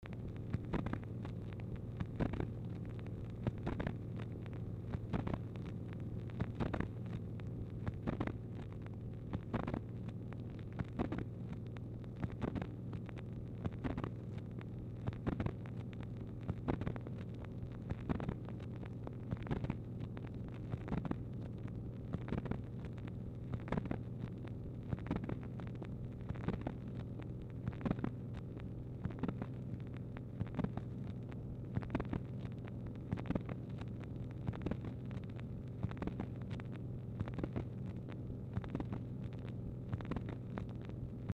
Telephone conversation # 3725, sound recording, MACHINE NOISE, 6/12/1964, time unknown | Discover LBJ
Format Dictation belt
White House Telephone Recordings and Transcripts Speaker 2 MACHINE NOISE